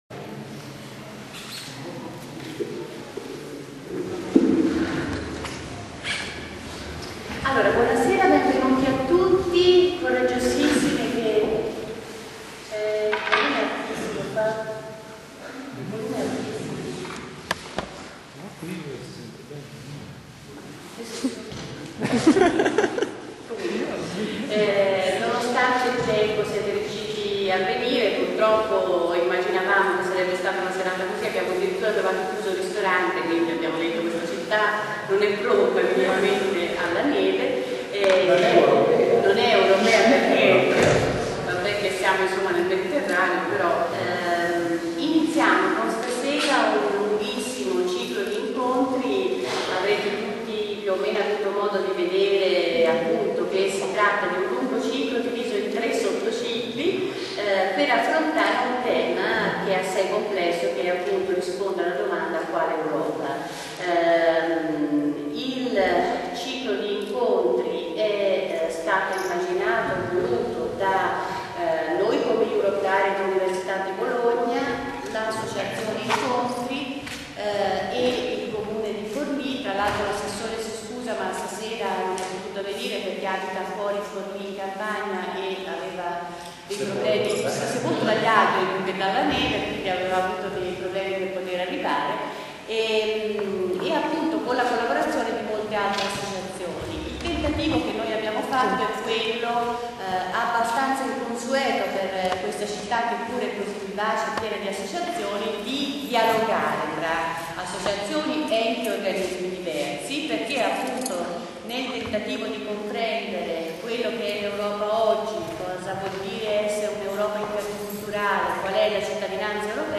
mercoledì 2 marzo 2011 - ore 21 - Sala Gandolfi, corso Diaz 45, Forlì